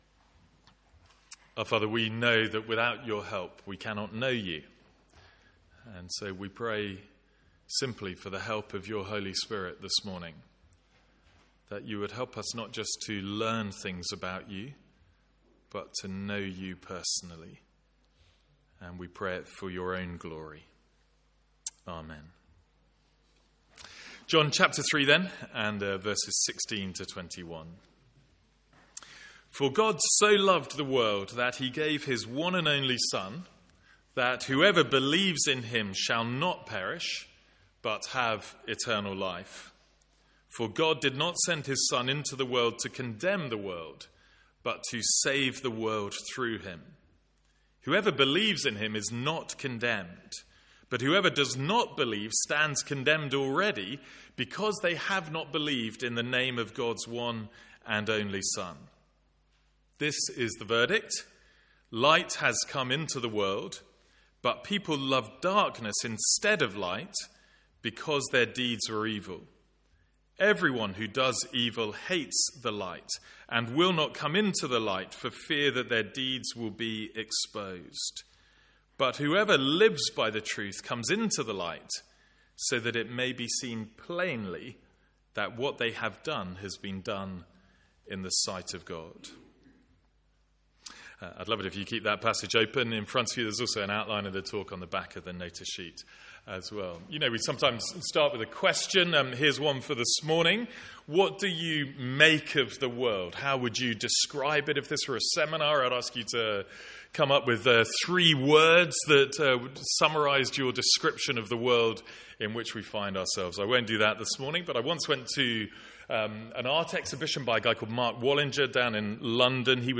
Sermons | St Andrews Free Church
From our morning series in John's Gospel.